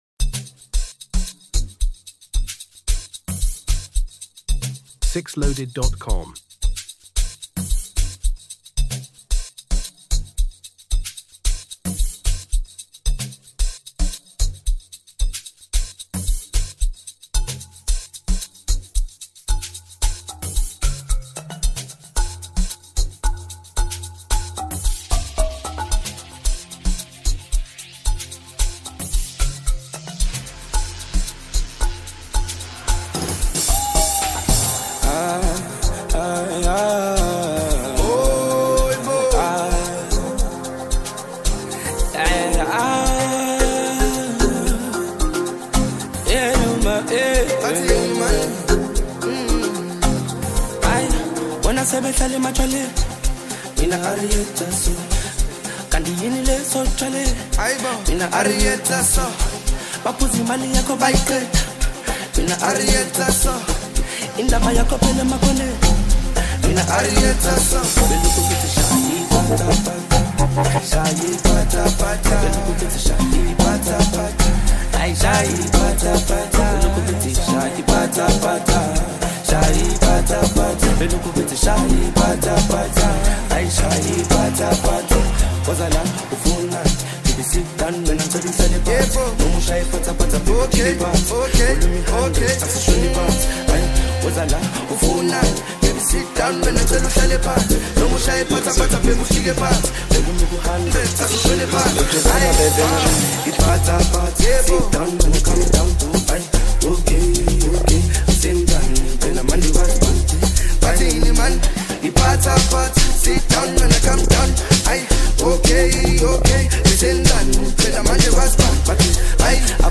Nigerian singer